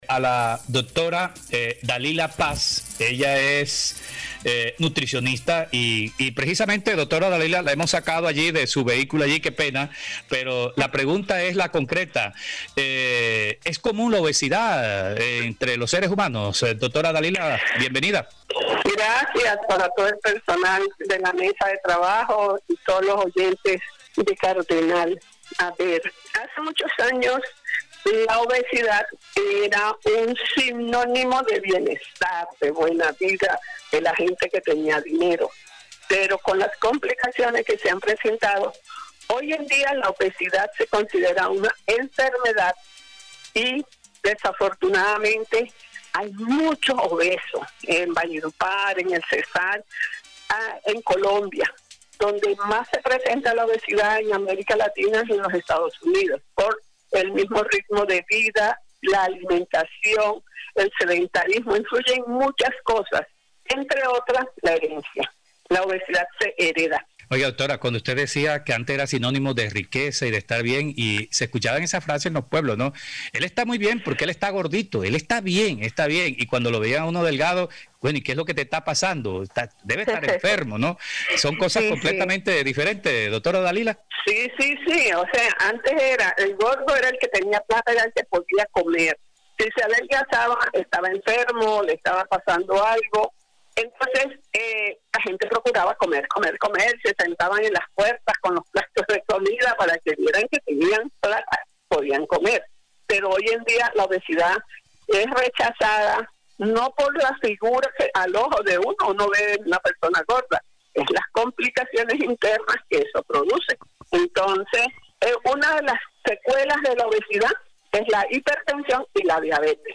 en conversación con el programa Cuarentena del Sistema Cardenal